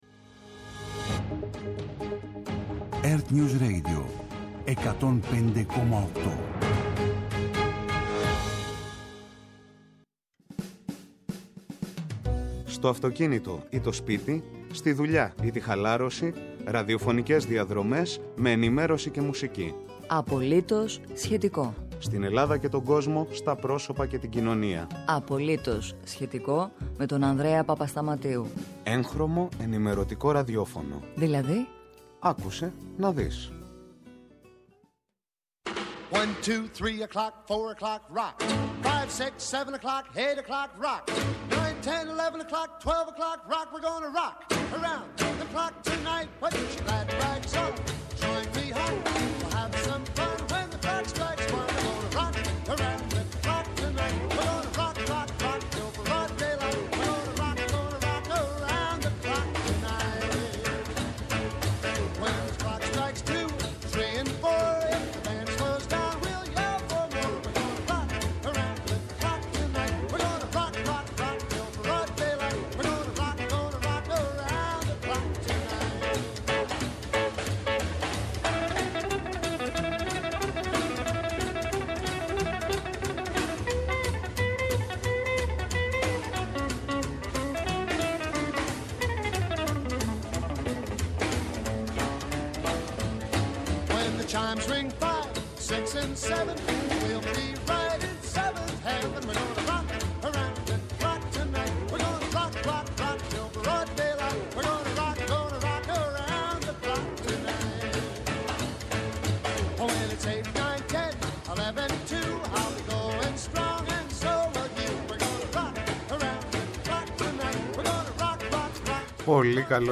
Εντάξει, αλλά περισσότερες από 40; Γίνεται; Και πως;Σήμερα στην εκπομπή, γνωρίζουμε και μιλάμε (στα ελληνικά) με τον άνθρωπο, που ίσως κατέχει το παγκόσμιο ρεκόρ γλωσσομάθειας!